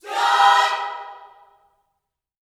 JOY CHORD3.wav